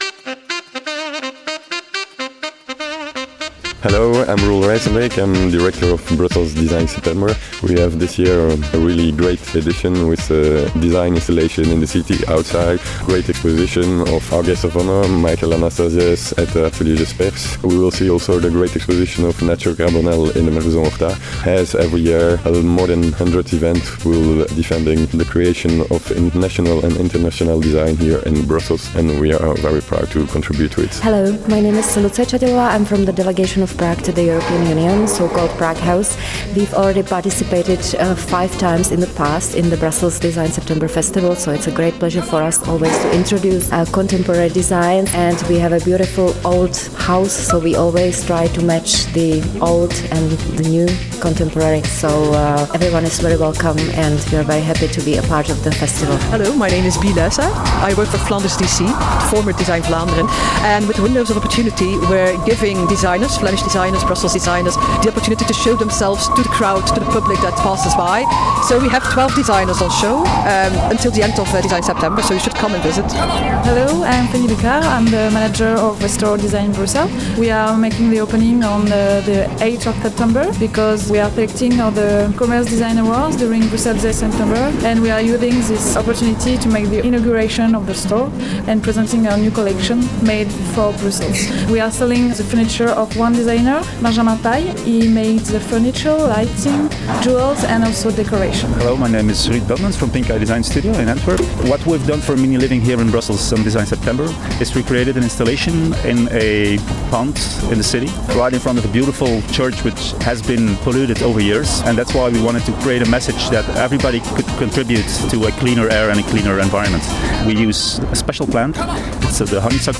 We hear from a dozen creators and organizers from among the over 100 events.